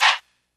Vox (R.I.P. SCREW).wav